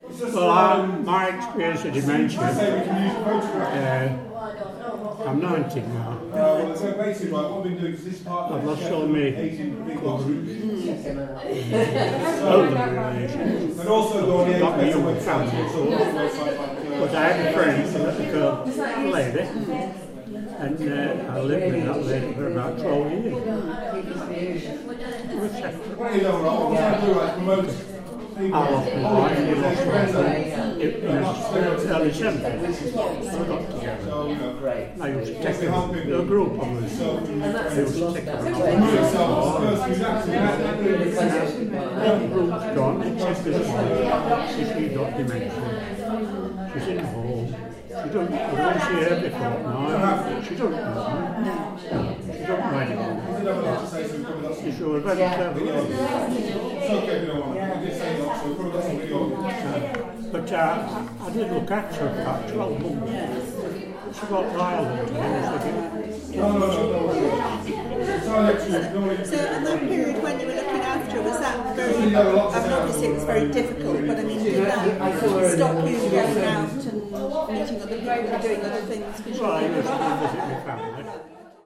Community workshop - Cause - Losing shared memories Story about Dementia.